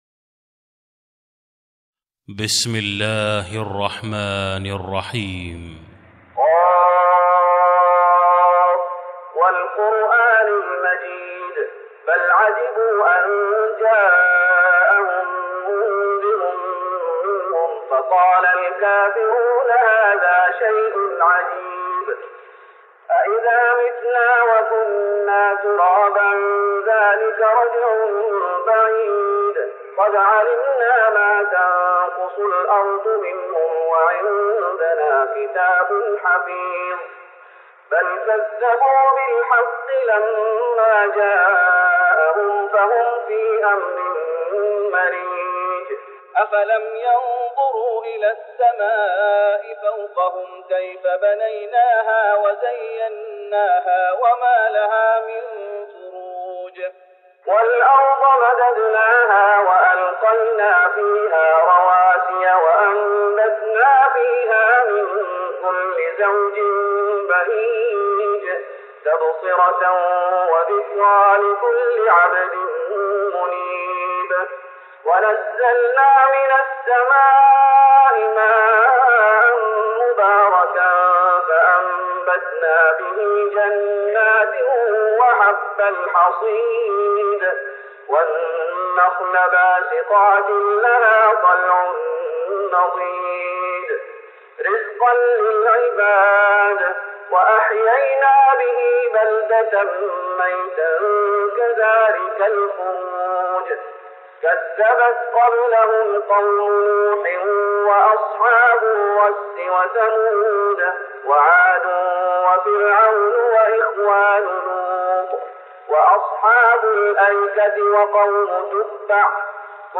تراويح رمضان 1414هـ من سورة ق Taraweeh Ramadan 1414H from Surah Qaaf > تراويح الشيخ محمد أيوب بالنبوي 1414 🕌 > التراويح - تلاوات الحرمين